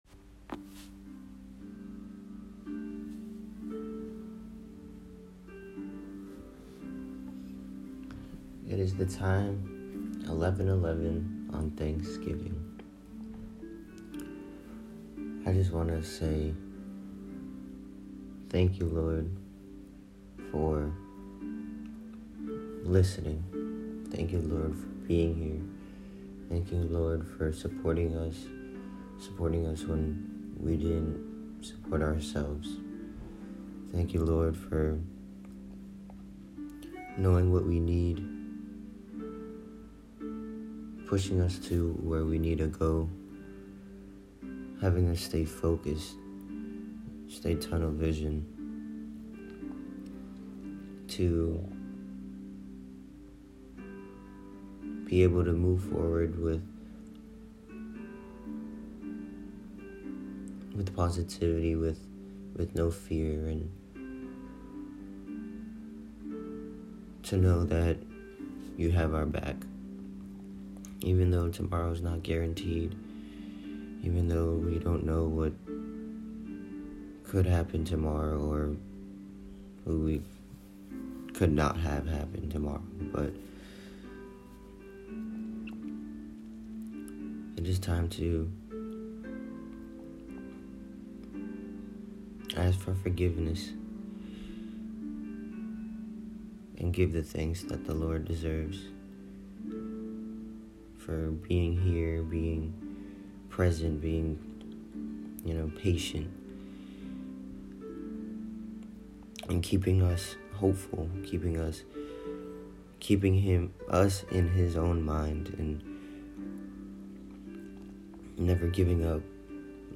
Here is a prayer from me to ya'll.